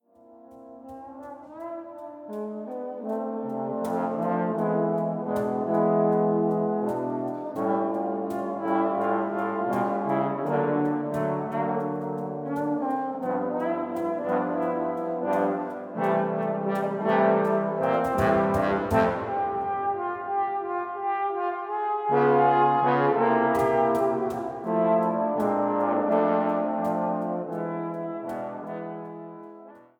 Bassposaune
Percussion/Cajon
Der typische weiche Klang des Posaunenquartetts